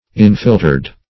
Infiltered - definition of Infiltered - synonyms, pronunciation, spelling from Free Dictionary